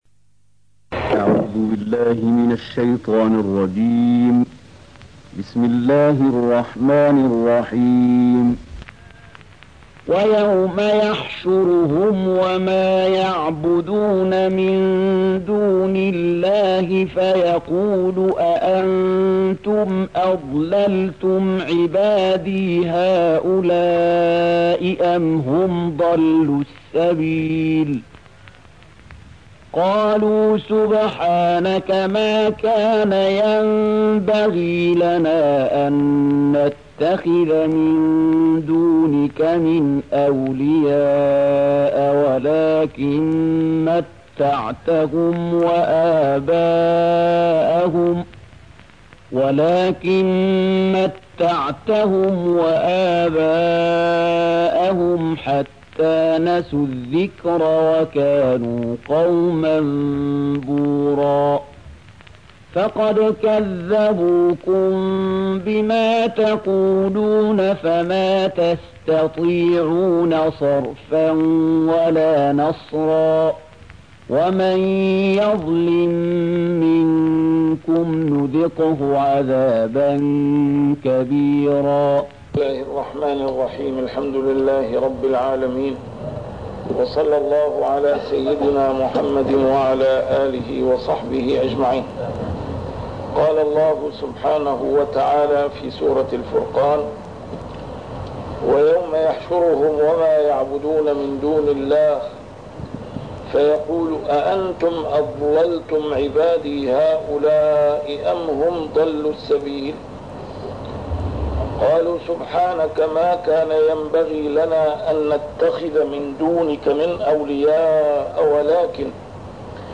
A MARTYR SCHOLAR: IMAM MUHAMMAD SAEED RAMADAN AL-BOUTI - الدروس العلمية - تفسير القرآن الكريم - تسجيل قديم - الدرس 205: الفرقان 17-19